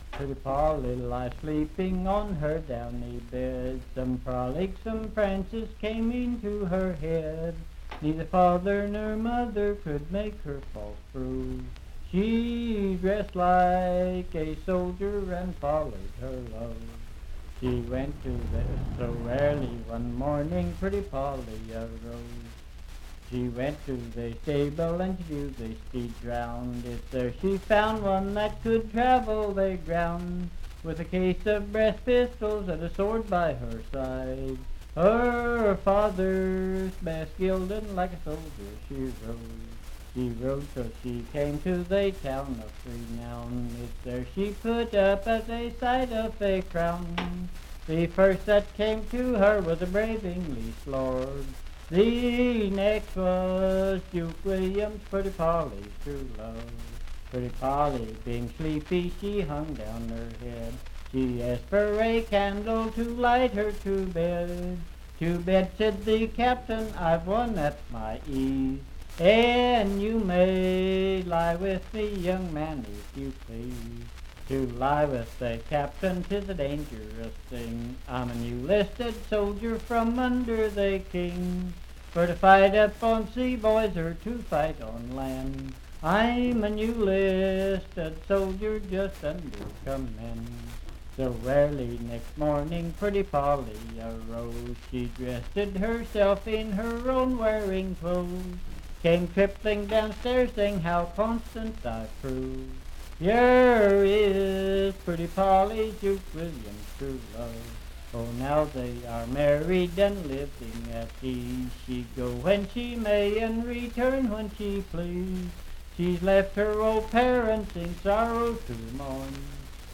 Unaccompanied vocal music
in Riverton, Pendleton County, WV.
Verse-refrain 7(4).
Voice (sung)